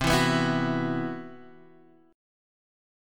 Esus2/C chord